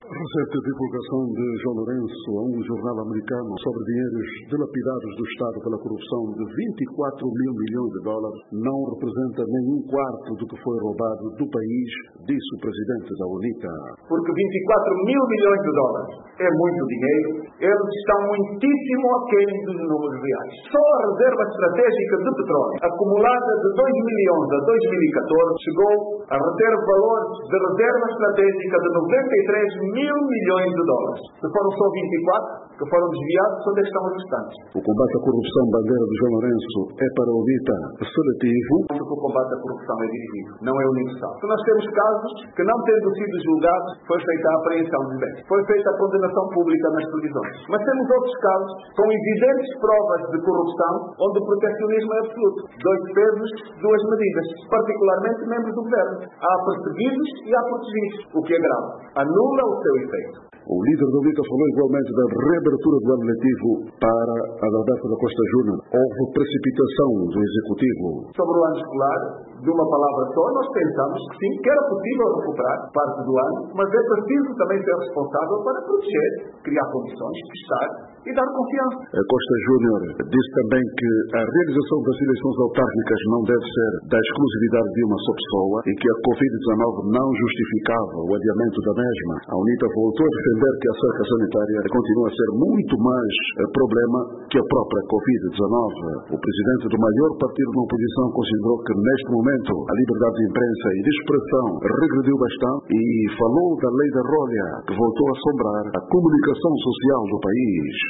Adalberto Costa Júnior em conferência de imprensa - 1:48